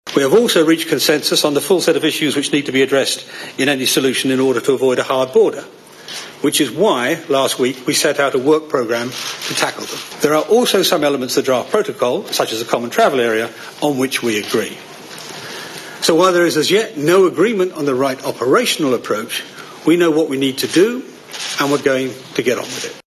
Britain’s chief negotiator David Davis outlines the work yet to be done over the border: